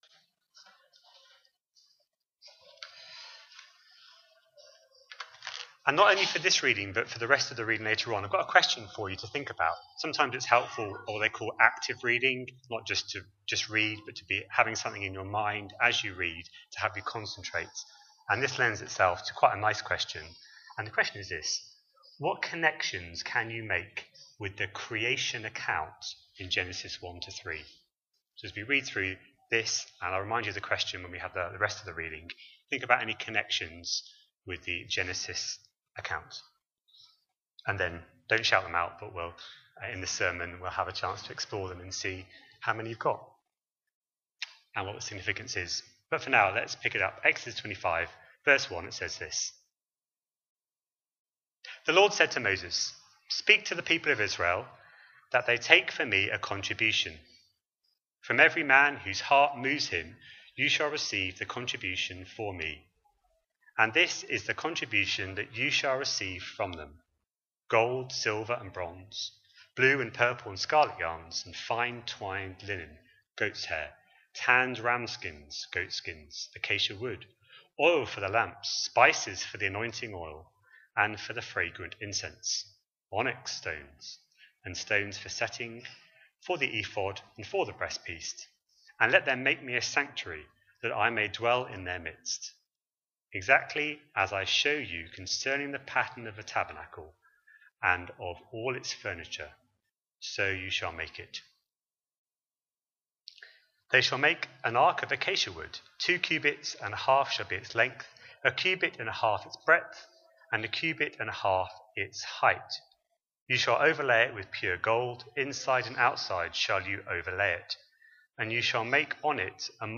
A sermon preached on 7th September, 2025, as part of our Exodus series.